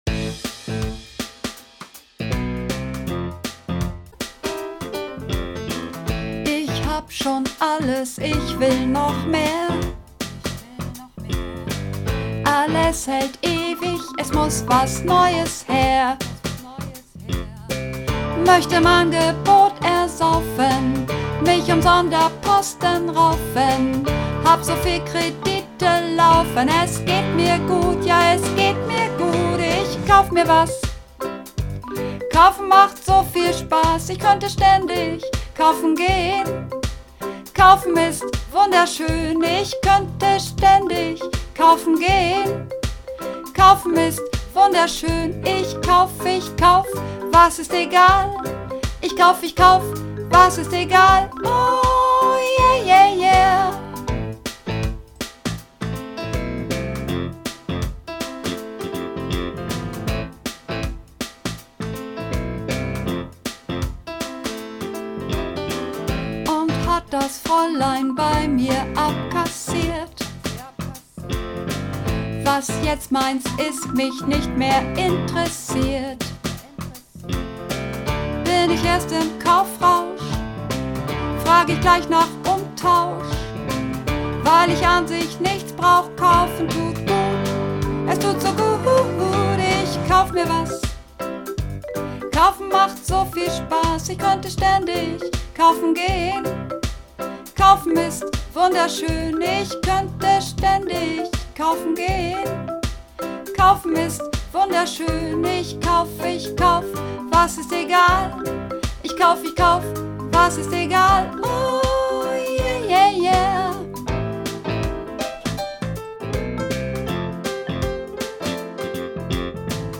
Übungsaufnahmen - Kaufen
Kaufen (Sopran - Intro kurz)